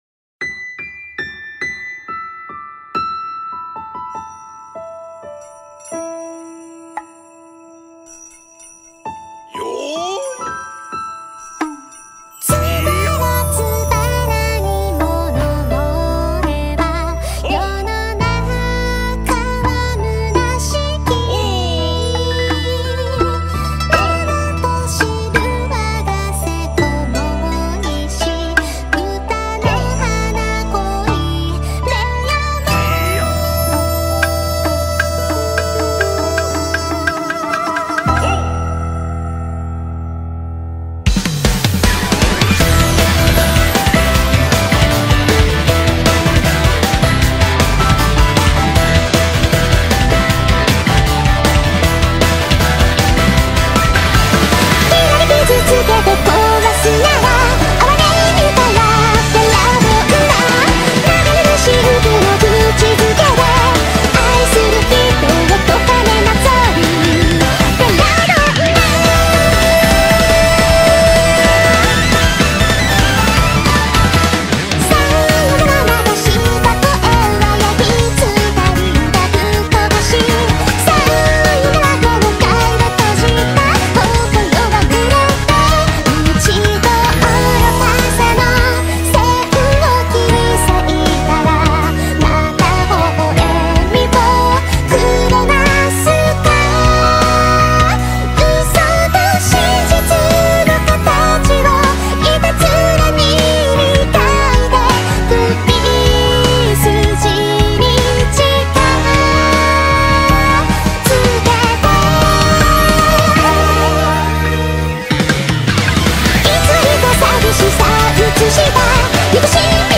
BPM78-156
Audio QualityPerfect (High Quality)